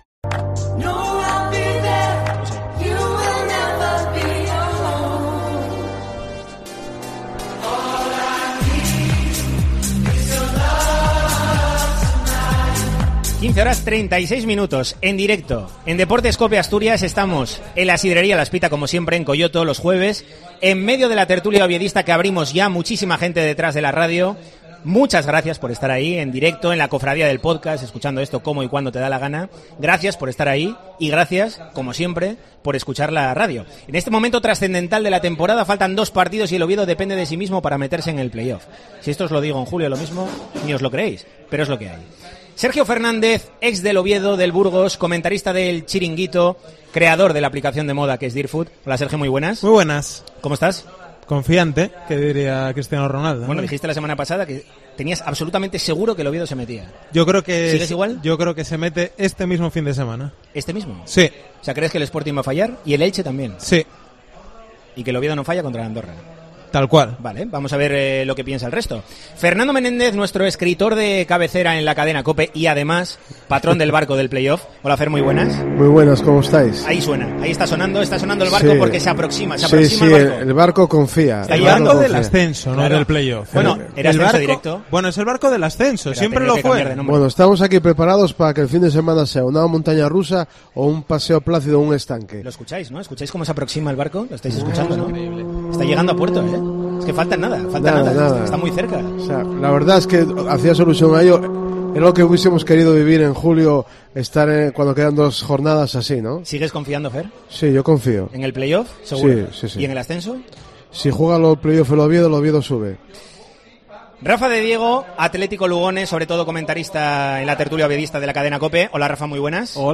'La Tertulia del Oviedo', en COPE Asturias En el capítulo de este jueves de 'La Tertulia del Oviedo' , desde la sidrería La Espita , en Deportes COPE Asturias , hablamos de los efectos de la derrota ante el Espanyol , la polémica arbitral que rodea al equipo y el estado de confianza de cara a los dos últimos partidos de liga.